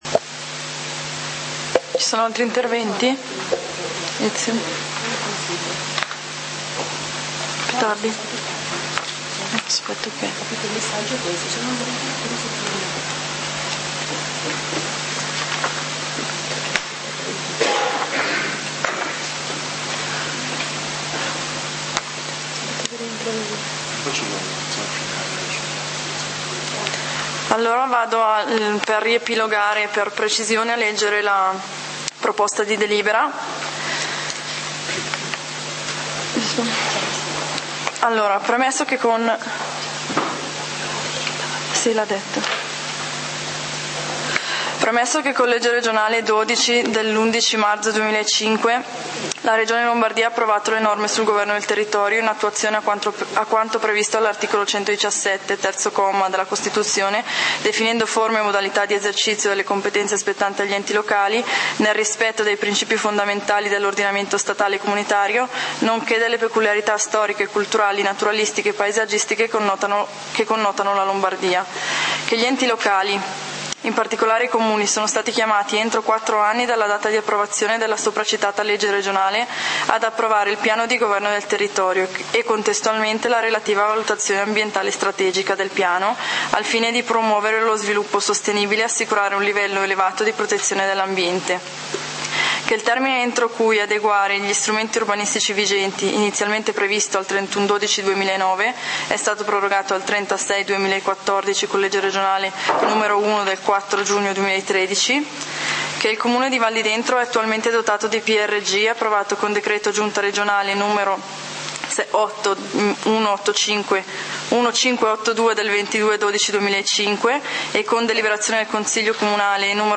Consiglio comunale di Valdidentro del 30 Giugno 2014
Consiglio comunale del 30 Giugno 2014 torna alla lista dei punti Punto 3a: Piano di governo del territorio adottato con deliberazione di consiglio comunale n. 49 del 30.12.2013. Esame e decisione in merito alle osservazioni e ai pareri pervenuti. Approvazione definitiva; Lettura della proposta di delibera.